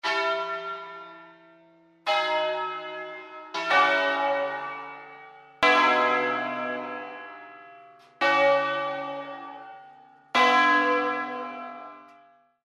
Entrando nello specifico di Bergamo, il suono ‘a scala’ si è sempre mosso sul principio del suono ‘al botto’, vale a dire producendo accordi invece di suoni alternati ed equidistanti:
Tre campane grosse
Vengono chiamate VI e VII. L’VIII parte quando sente il primo botto della VI.